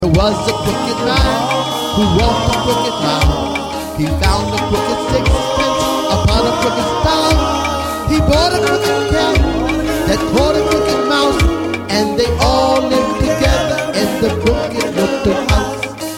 Children's Nursery Rhyme and Sound Clip